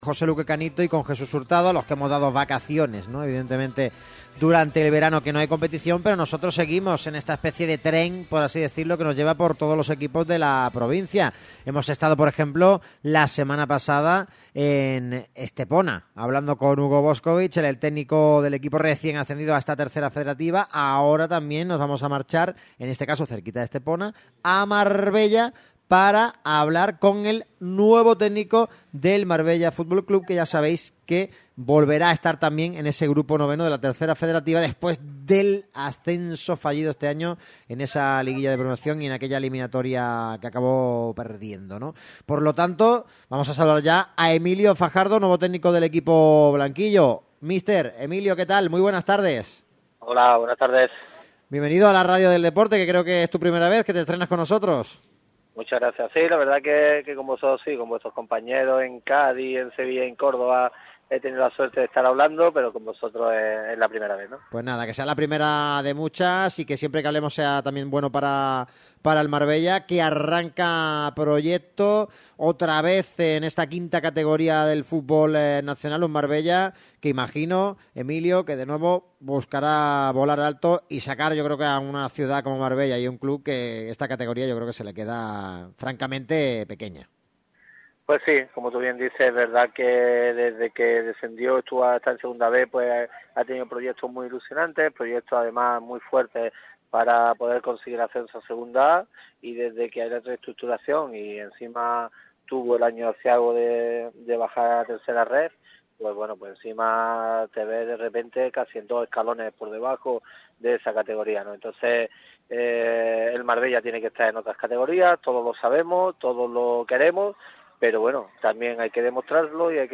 pasó por el micrófono rojo de Radio MARCA Málaga para dejar su impronta sobre la próxima temporada del conjunto malagueño en 3ª RFEF.